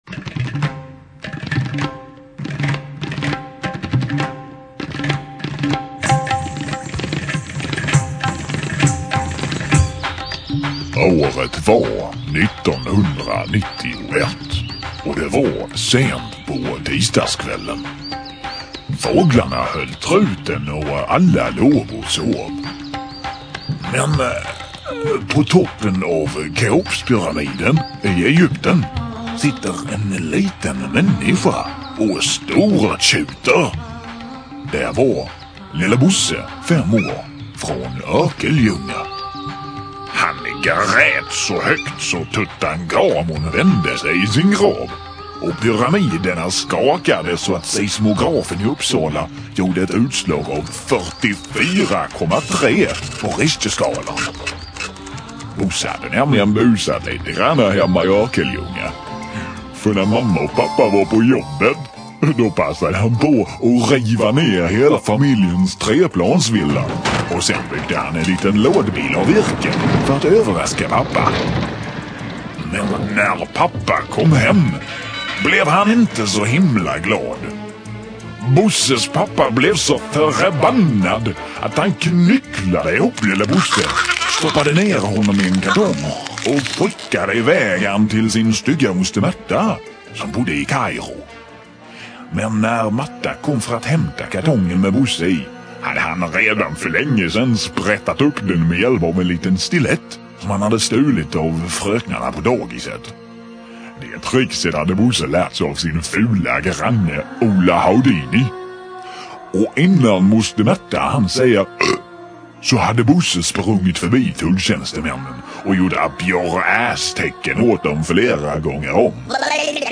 "Lelle Bosse" berättelser
Lo-fi filerna är samma som originalet men i mono och lite sämre ljudkvalisort - snabbare att ladda hem.